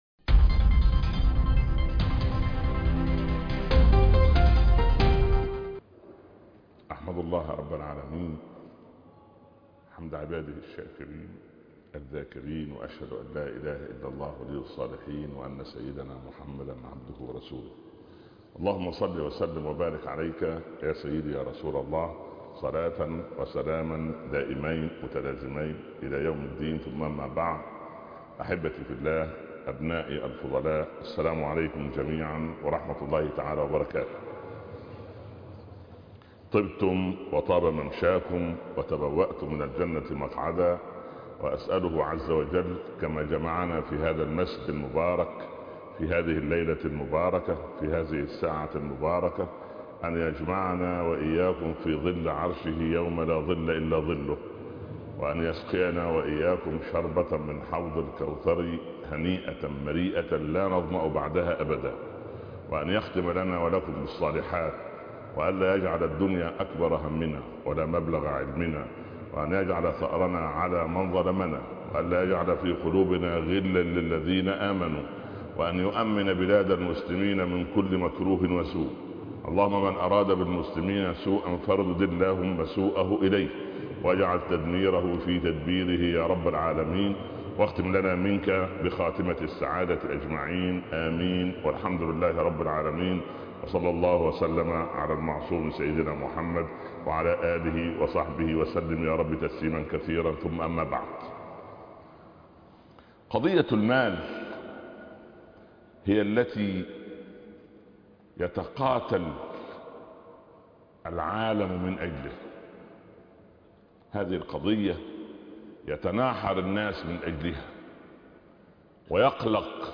المال - محاضرة رائعة للشيخ بقطر - الشيخ عمر بن عبدالكافي